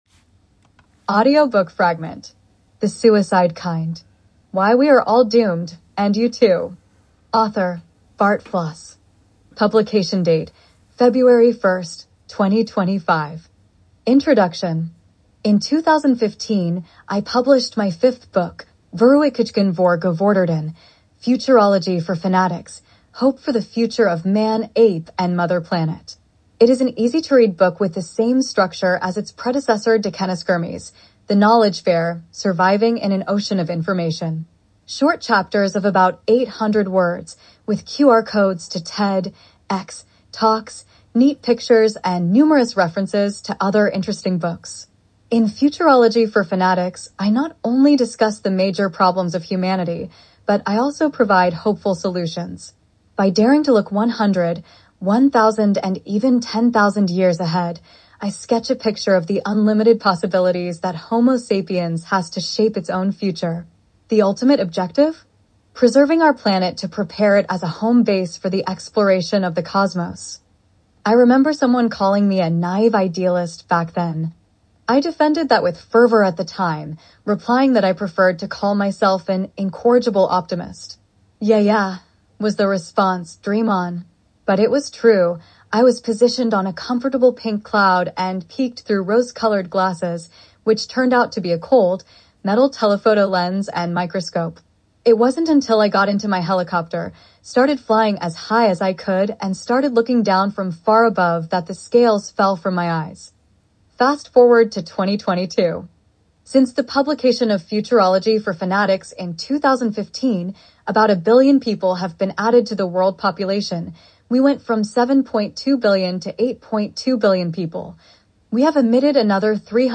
Audio-Book-Fragment_2-The-Suicide-Kind-V_1-Introduction.m4a